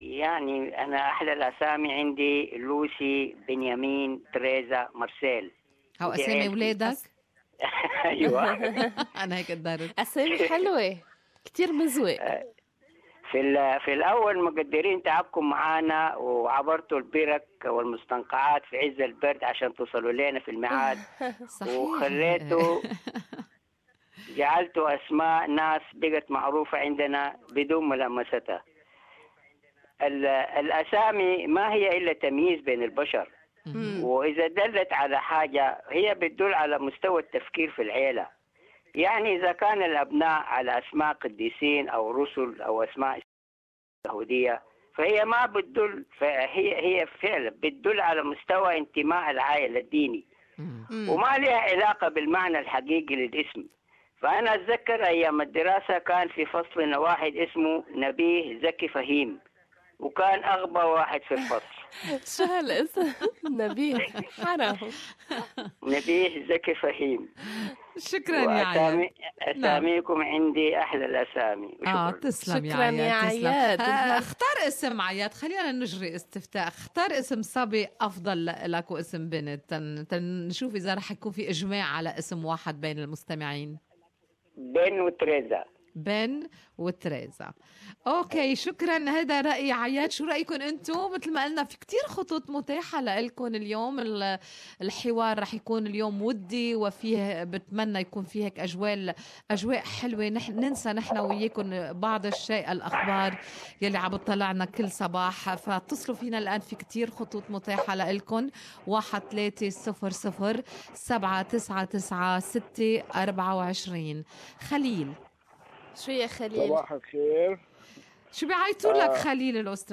Thursday talk back segment